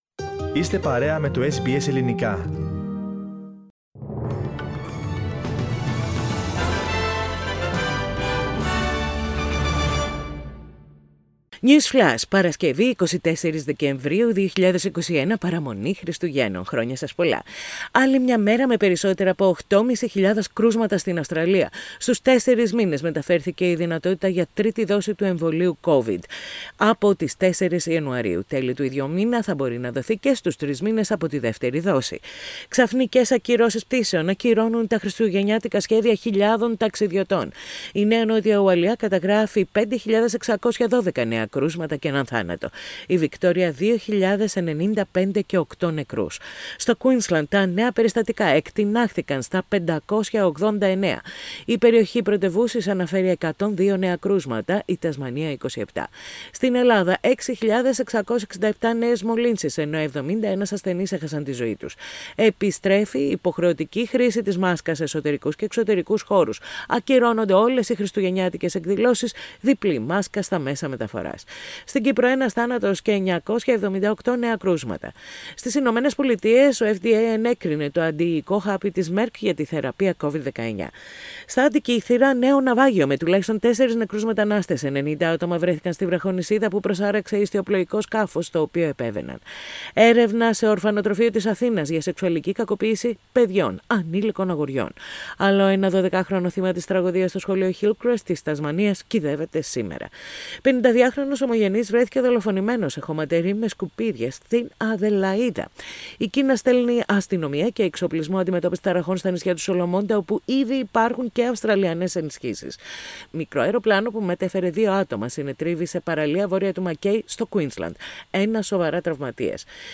News Flash in Greek. Source: SBS Radio